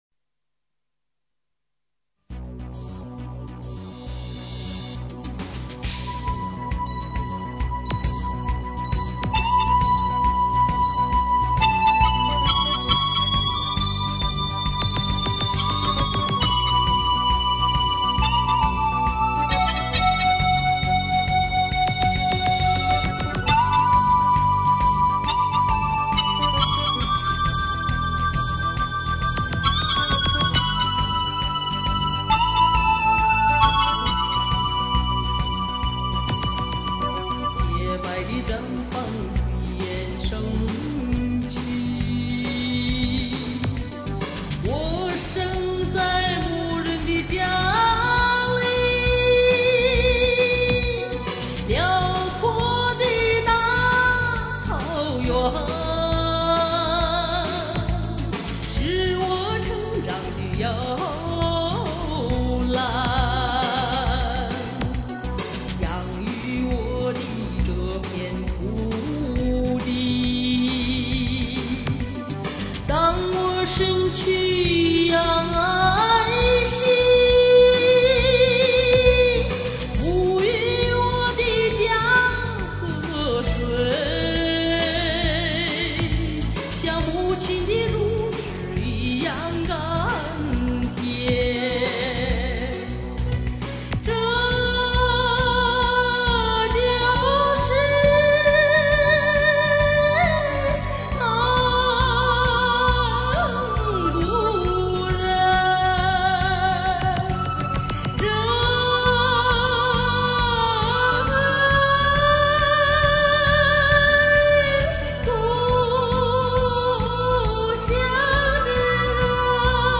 国语 蒙古语演唱
精选最原汁原味的草原歌曲，蒙古族几十位著名音乐家倾情演唱，琴曲悠扬笛声脆，晚风吹送天河的星啊，汇入毡房闪银辉。